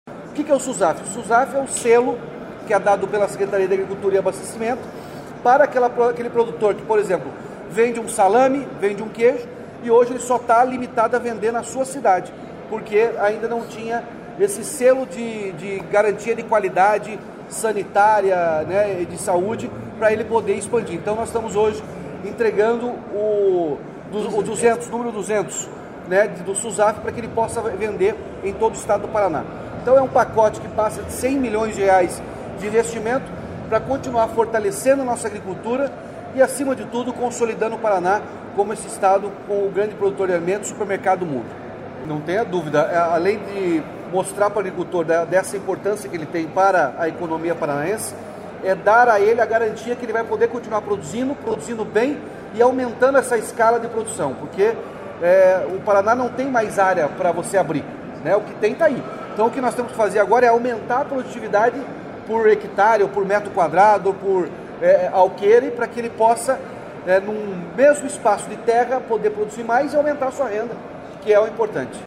Sonora do governador Ratinho Junior sobre a entrega do Selo Susaf a Campo Mourão, 200ª cidade a receber a certificação